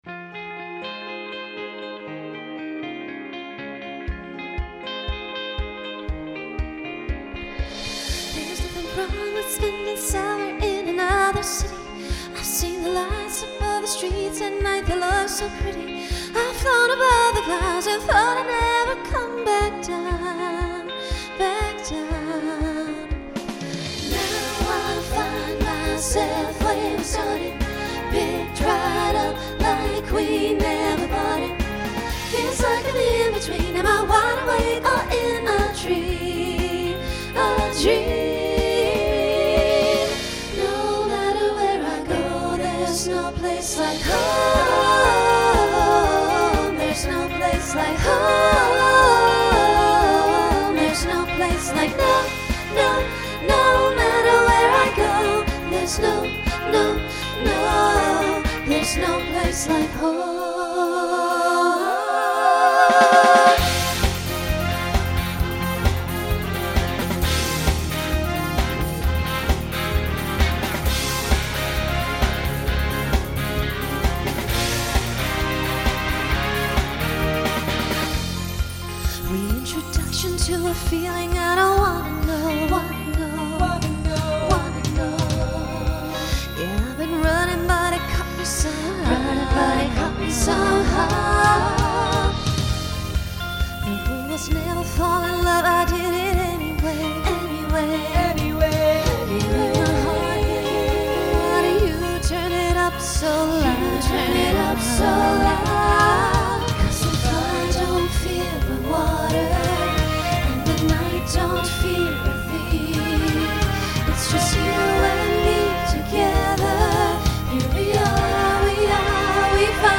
New SSA voicing for 2020